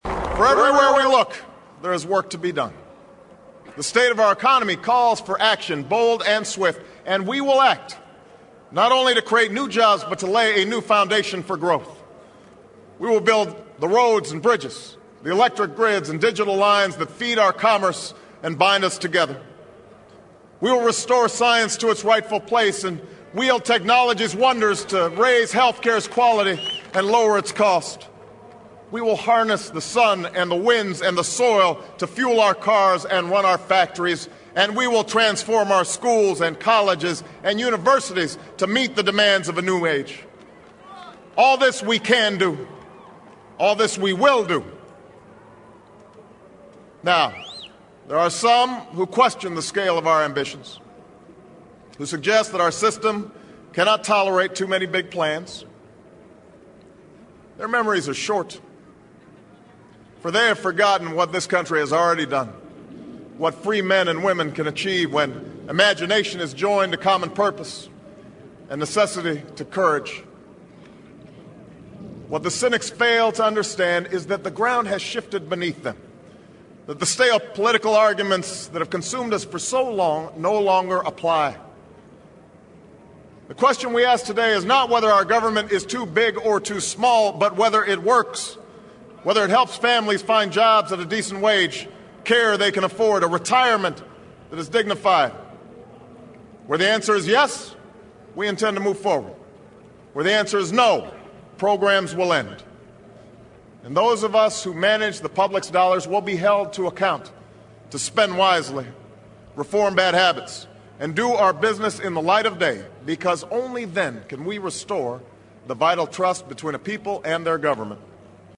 偶像励志英语演讲 第108期:美国总统奥巴马就职演说(4) 听力文件下载—在线英语听力室